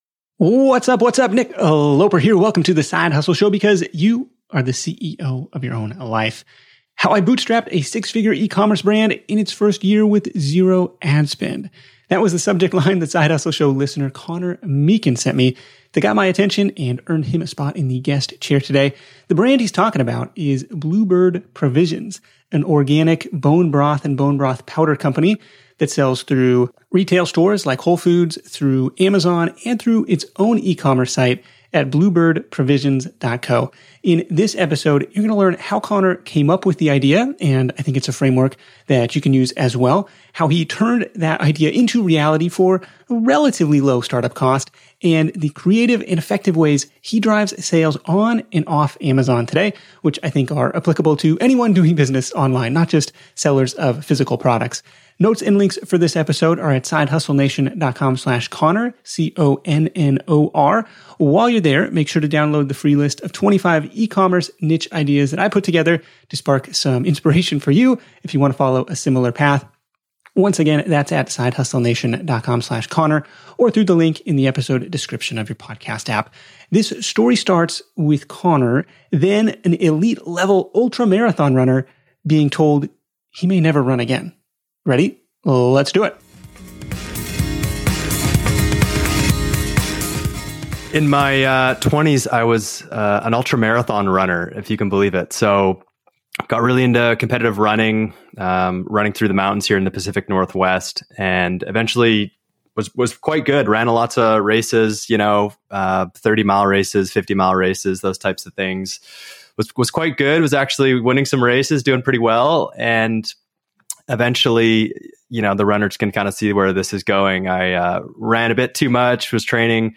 Tune in to The Side Hustle Show interview